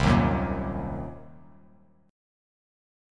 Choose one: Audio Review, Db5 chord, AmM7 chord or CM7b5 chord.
CM7b5 chord